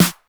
snr_62.wav